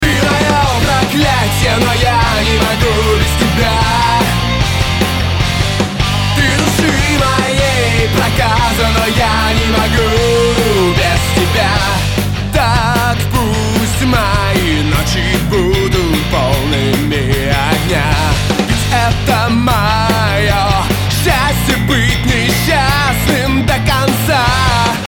Убрать шумы в сэмпле